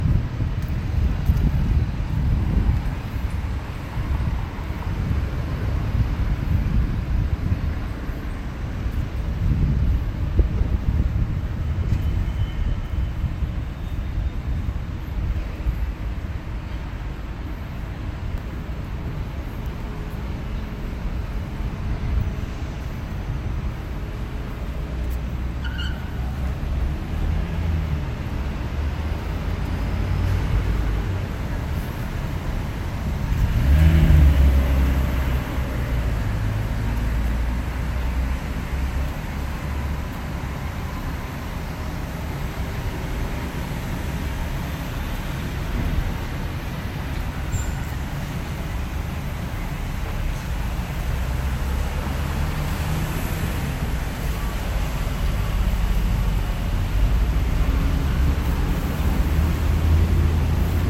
描述：当建筑商翻修我公寓附近的街道时，快速进行现场录音。索尼PCMD50
Tag: 建造 建设者 城市 城市景观 现场录音 鹿特丹 街道